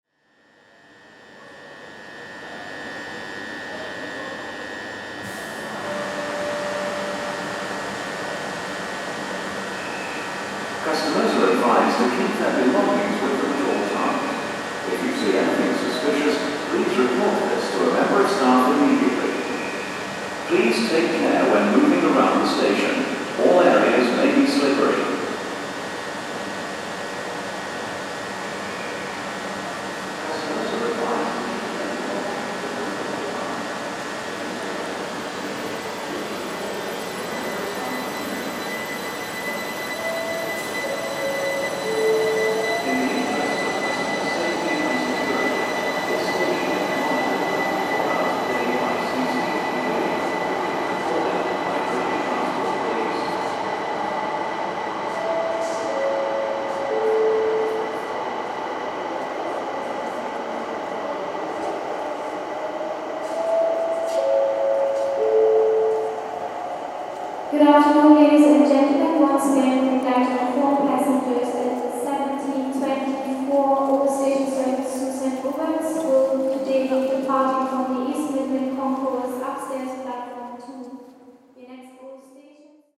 At St Pancras International
Tags: Sound Map in London London sounds UK Sounds in London London